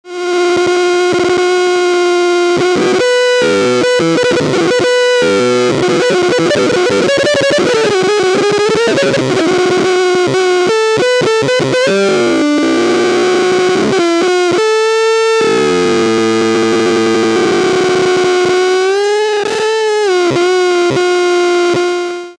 Citation : pfruuuuuuitttt fraaaaat skuiiiiiizzzzz abelelelelele
noiseresolve.wav.mp3